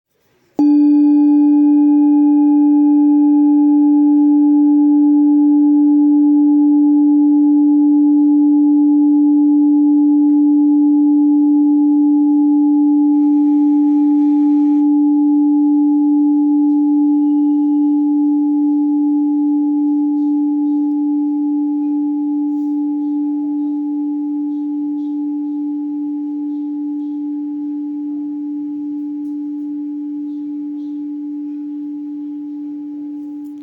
Singing Bowl, Buddhist Hand Beaten, Moon Carved, Antique Finishing
Material Seven Bronze Metal